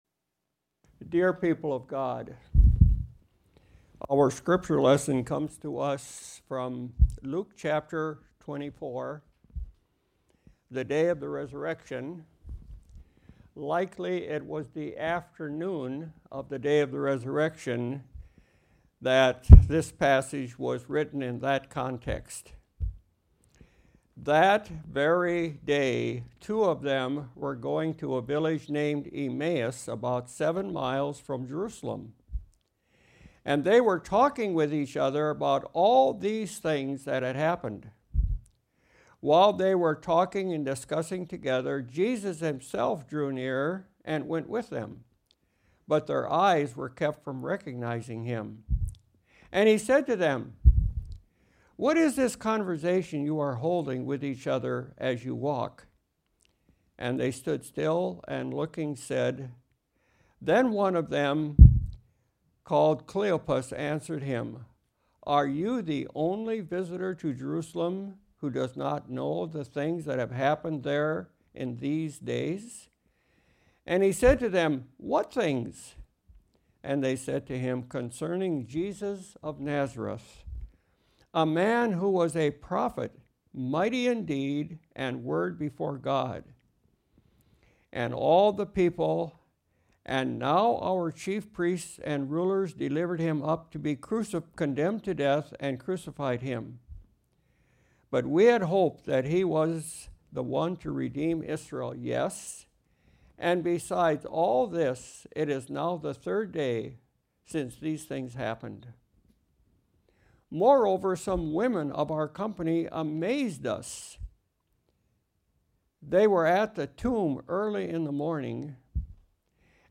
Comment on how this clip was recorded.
Luke 24:13-36 Service Type: Sunday Morning Outline